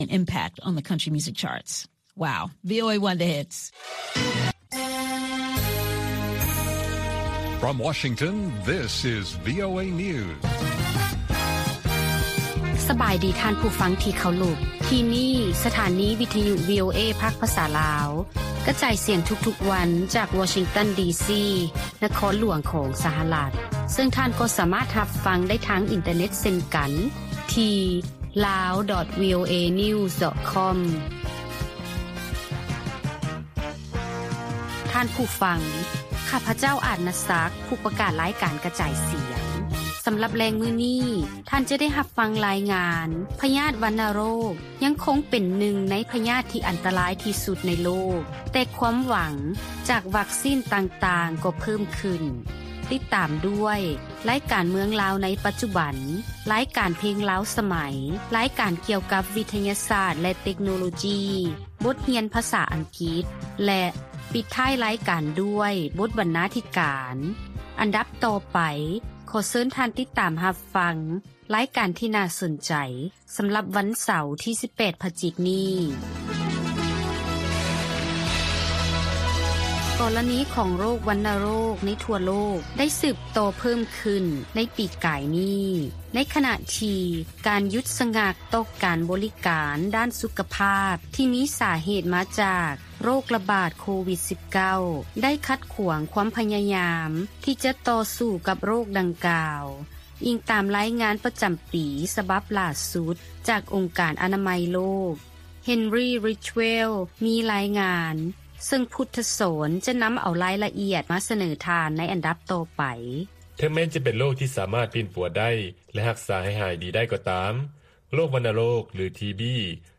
ລາຍການກະຈາຍສຽງຂອງວີໂອເອລາວ ວັນທີ 18 ພະຈິກ 2023